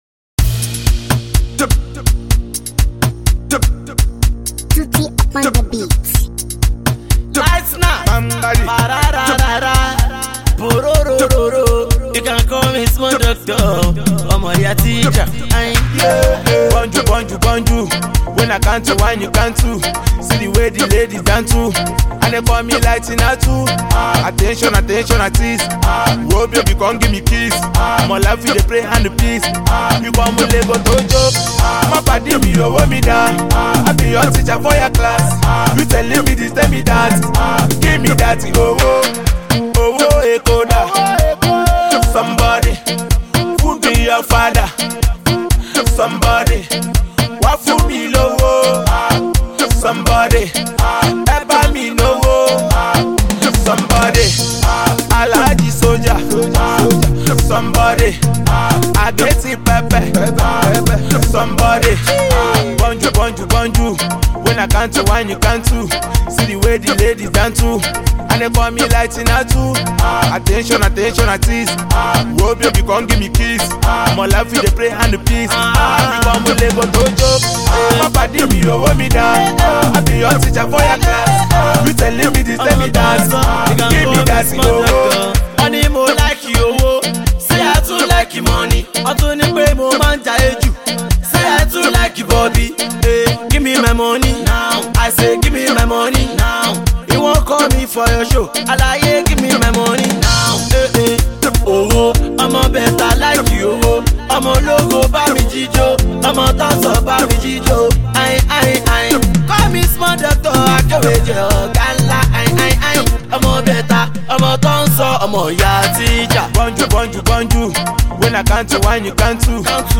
another banger on a groovy sound